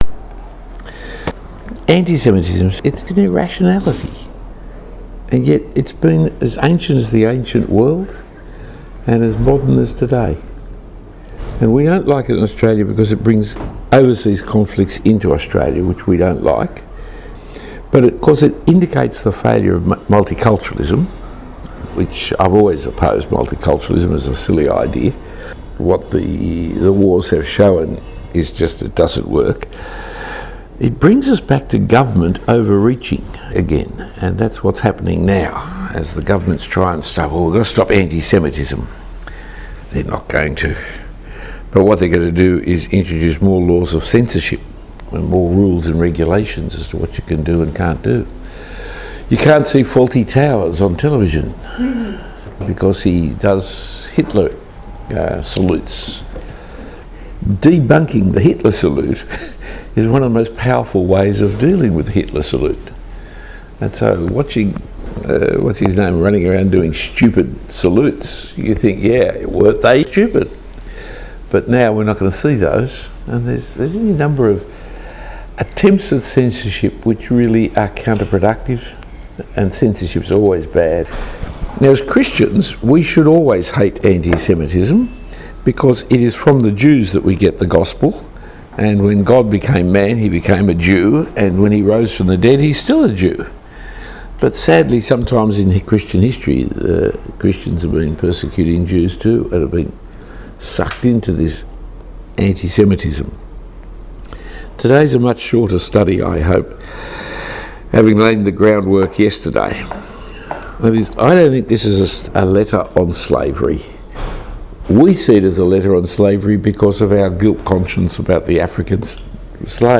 The first talk on Philemon is Why is Philemon in the Bible?